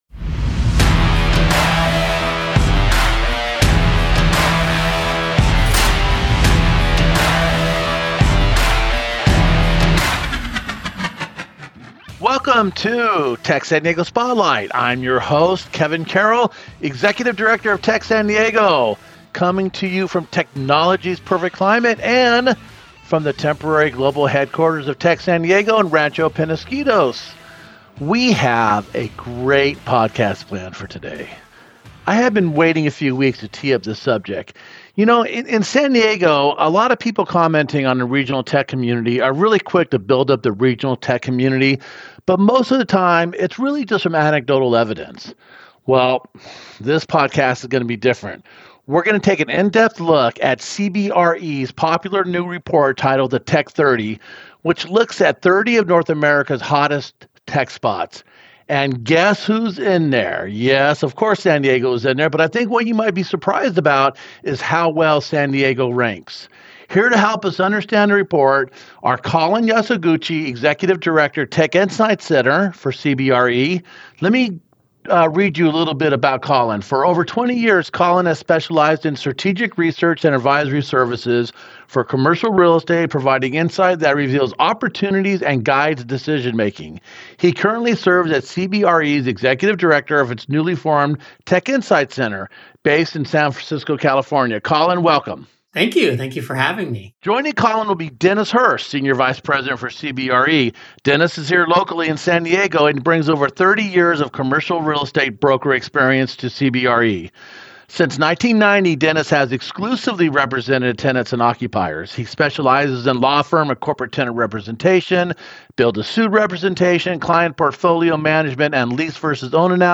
sat down remotely